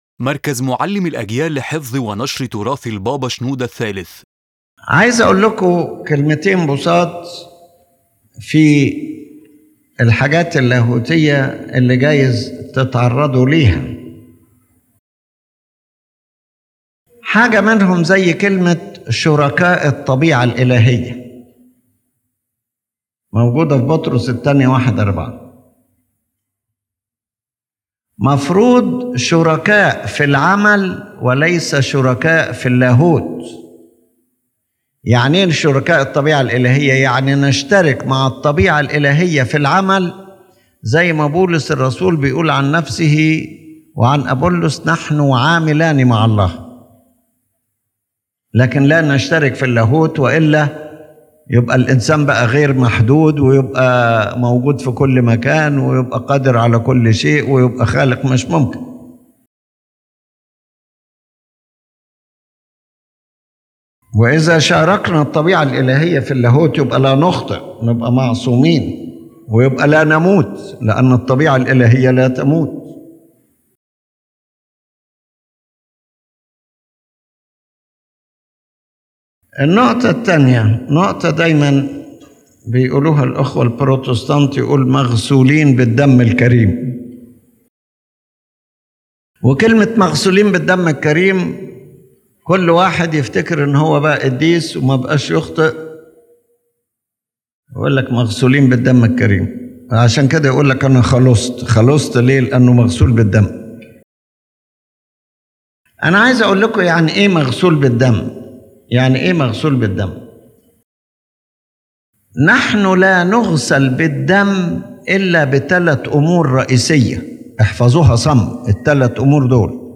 In this lecture His Holiness Pope Shenouda III comments on two theological phrases you may encounter: “partners of the divine nature” and “washed by the precious blood,” clarifying their correct meanings from the Orthodox perspective.